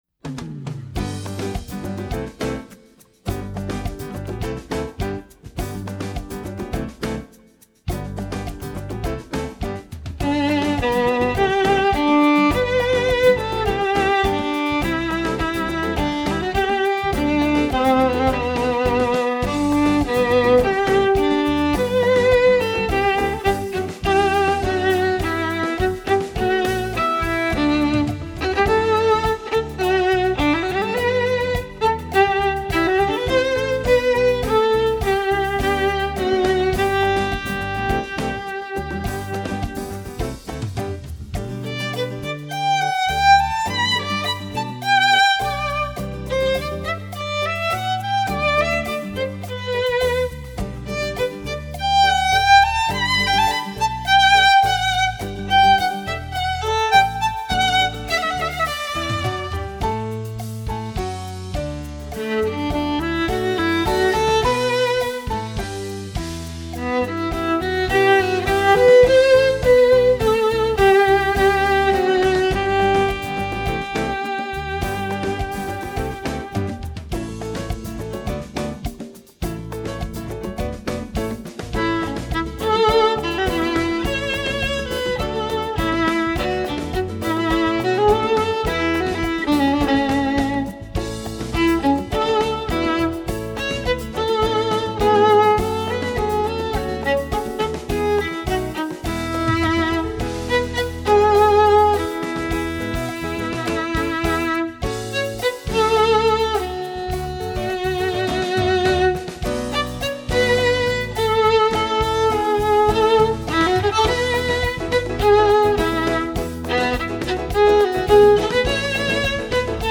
Voicing: Violin w/e